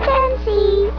!!!!!!!!!!POKEMON SOUNDS!!!!!!!!!!
chansey.wav